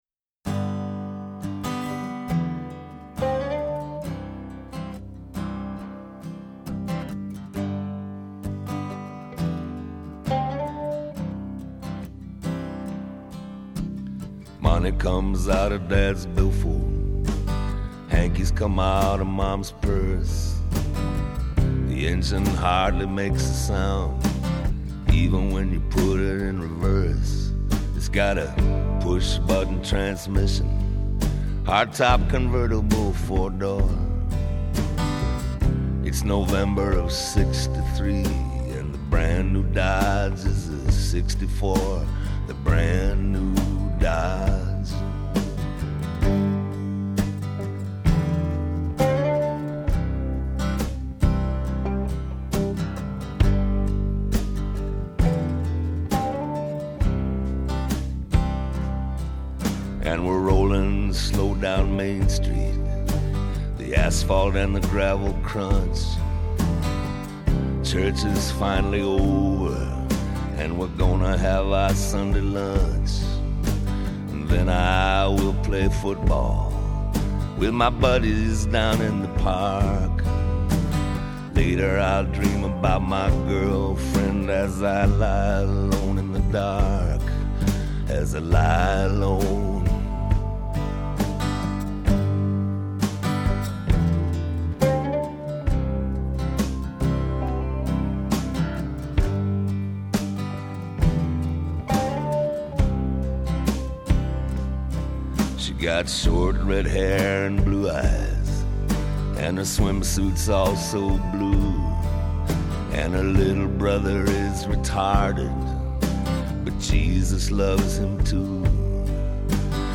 ★ 發燒友瘋狂推薦，栩栩如生、歷歷在目的錄音！
★ 類比之聲的示範級錄音，最溫暖豐富的活生感！